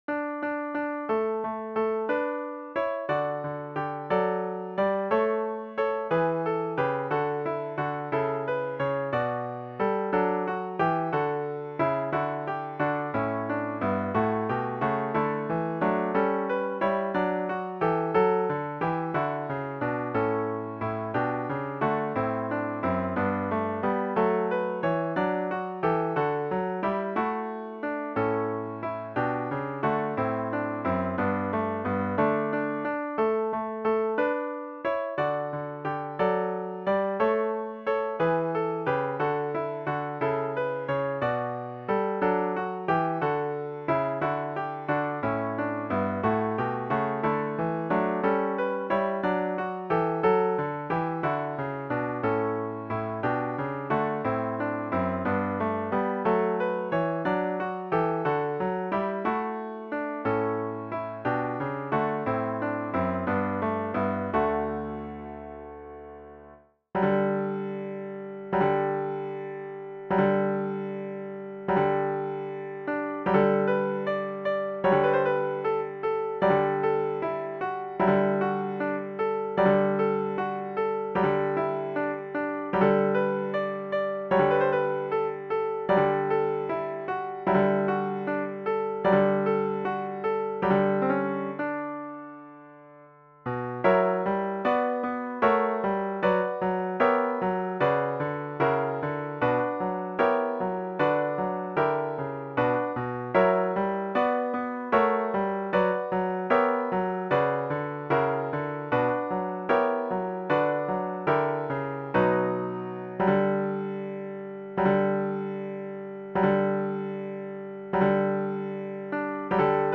for piano (organ) (3, 4 parts)